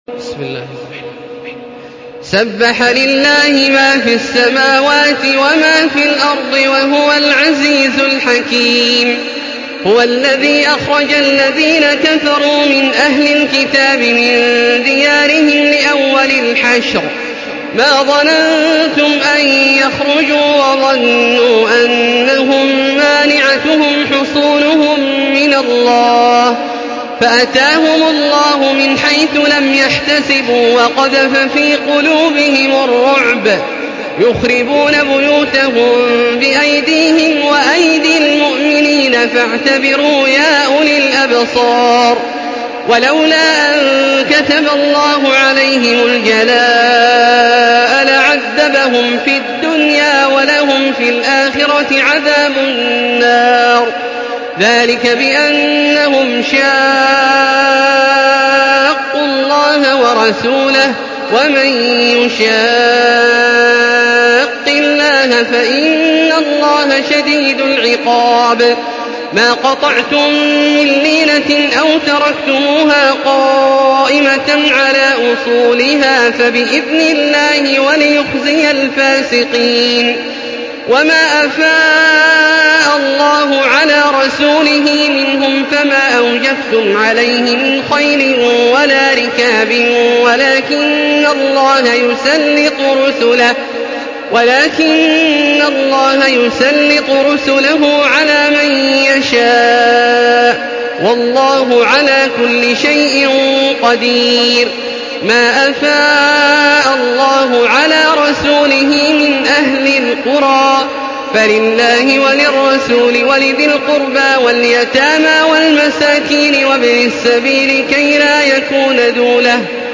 Surah আল-হাশর MP3 in the Voice of Makkah Taraweeh 1435 in Hafs Narration
Murattal Hafs An Asim